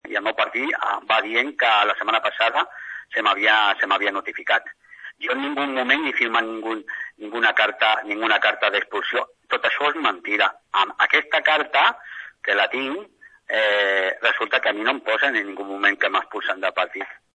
Per això, el polític palafollenc assegura que la direcció ha aprofitat aquesta situació per treure-se’l de sobre. Escoltem Oscar Bermán.